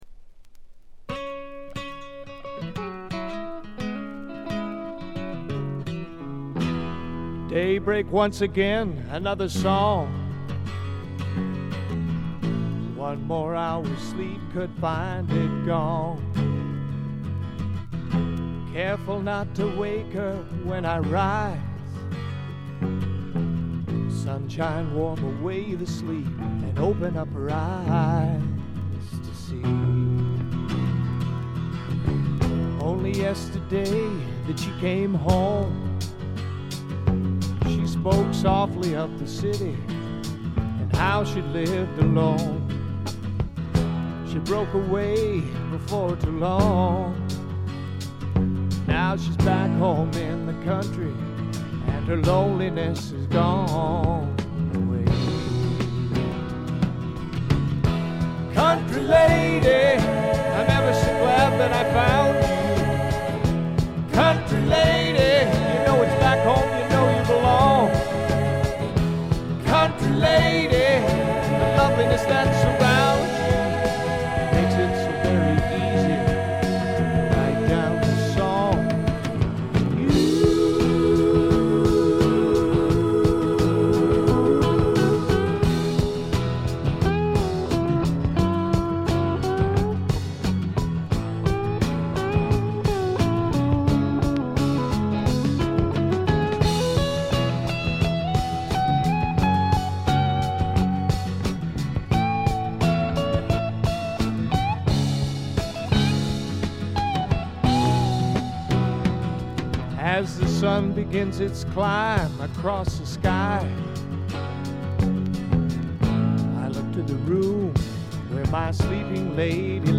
静音部で軽微なバックグラウンドノイズが少し。
乾ききった硬質感で統一された見事なスワンプロック！
試聴曲は現品からの取り込み音源です。